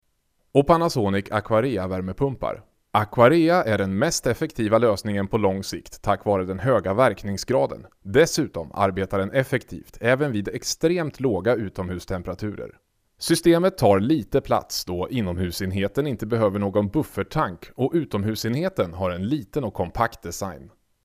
Swedish male voice overs
Swedish male voice over